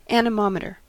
Ääntäminen
IPA : /ænɪˈmɒmətə/
IPA : /ænɪˈmɑːmətɚ/